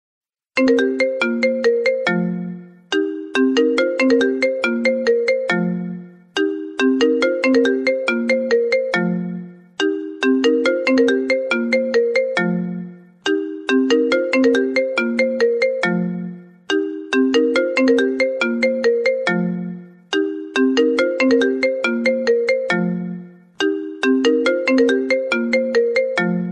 ringtone.mp3